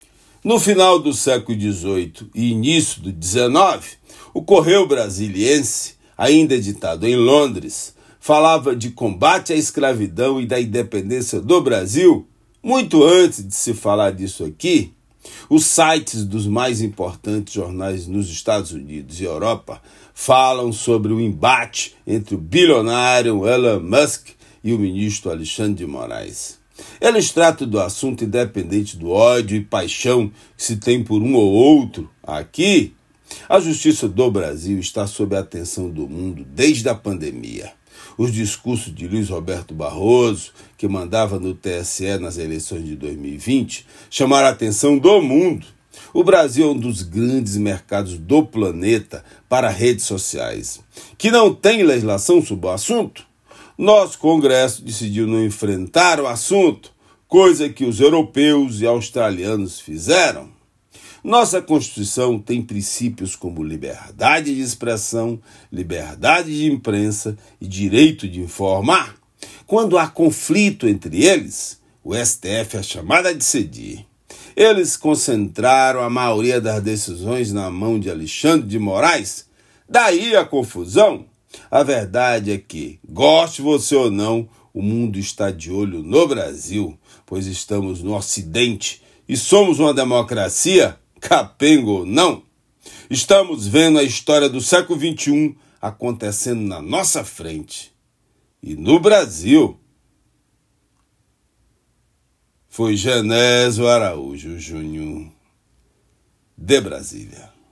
direto de Brasília.